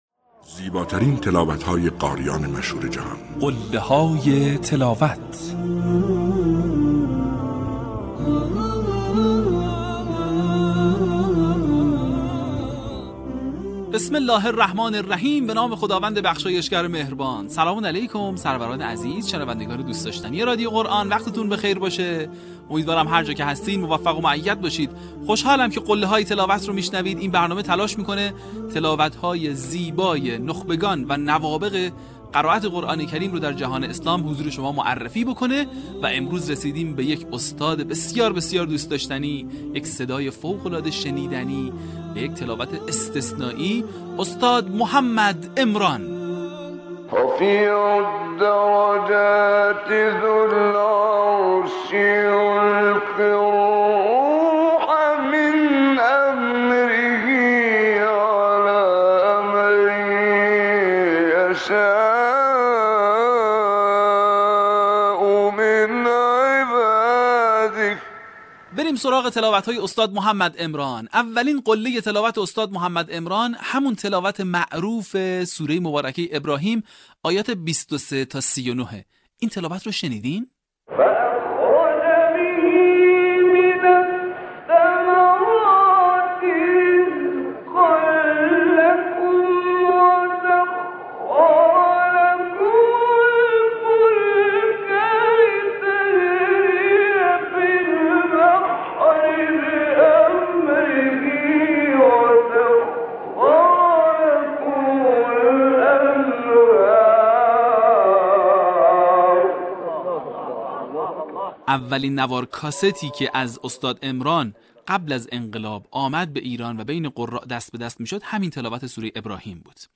به همین منظور برترین و برجسته‌ترین مقاطع از تلاوت‌های شاهکار قاریان بنام جهان اسلام که مناسب برای تقلید قاریان است با عنوان «قله‌های تلاوت» ارائه و بازنشر می‌شود. در قسمت بیست‌وششم فراز‌های شنیدنی از تلاوت‌های به‌یاد ماندنی استاد محمد عمران را می‌شنوید.
برچسب ها: محمد عمران ، قله های تلاوت ، تلاوت های ماندگار ، تلاوت تقلیدی